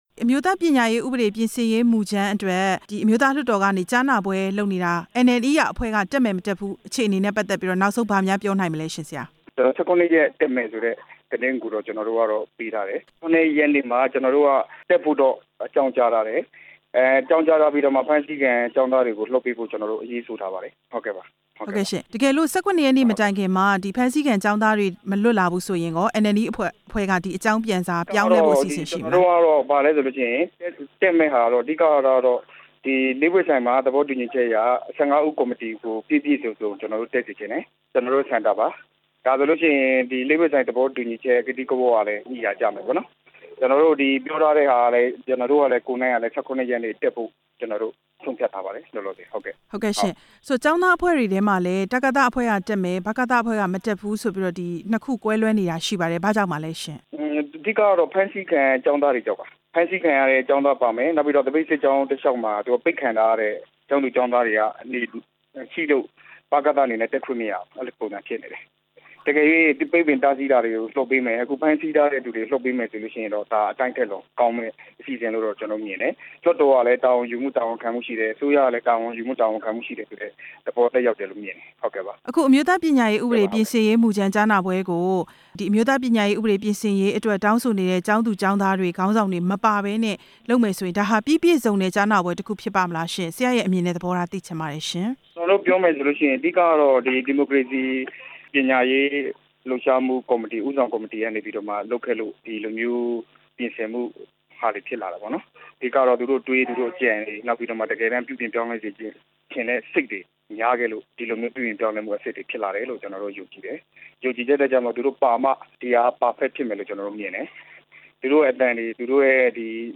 မတ်လ ၁၇ ရက်နေ့ လွှတ်တော်ကြားနာပွဲ တက်ရောက်မယ့် NNER အဖွဲ့နဲ့ မေးမြန်းချက်